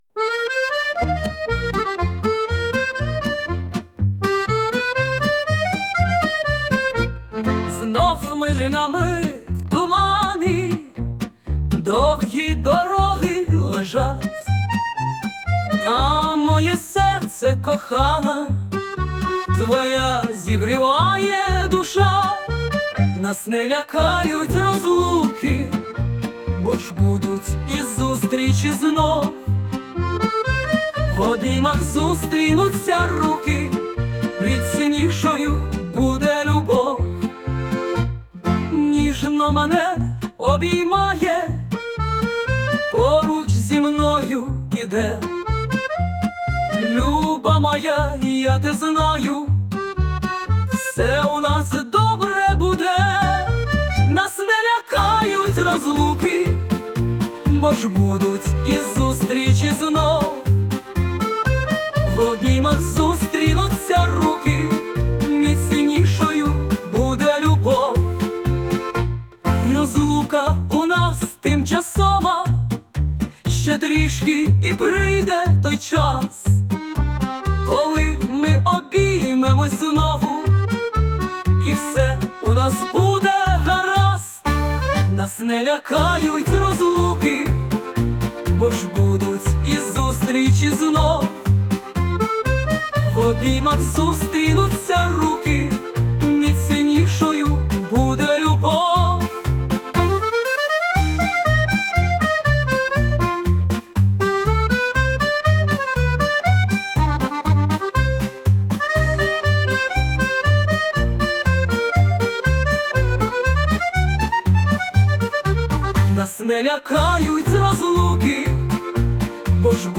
12 16 добре є! дійсно гарно і досить мелодійно! щасти! hi